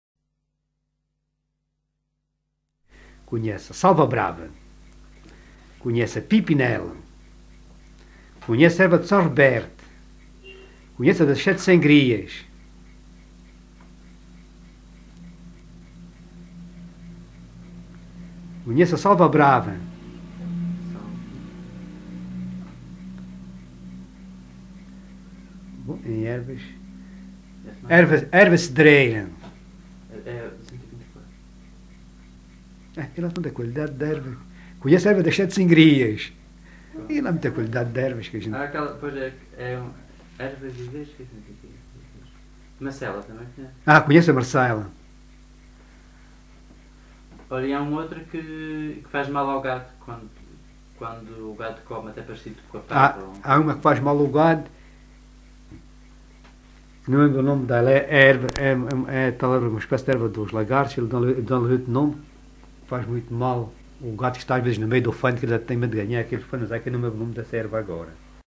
LocalidadeNisa (Nisa, Portalegre)